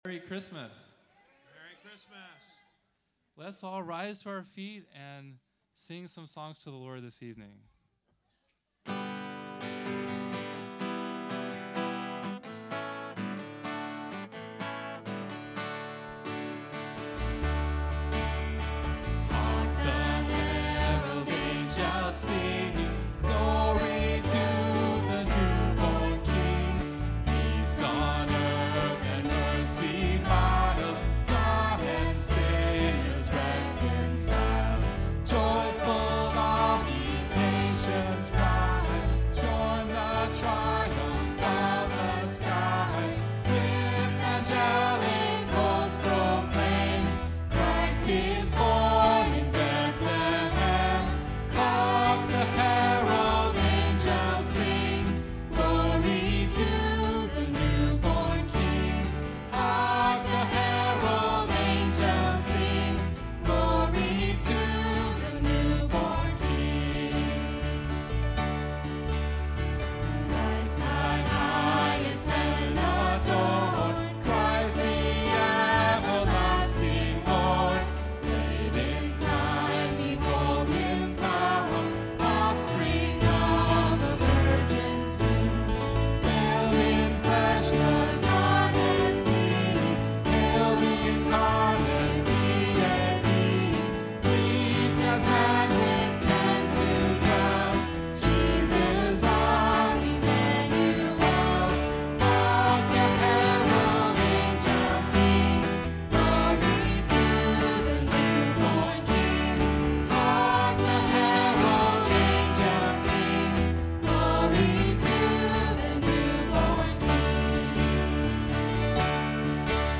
Sermons - Lighthouse Covenant Church
1224 Christmas Eve.mp3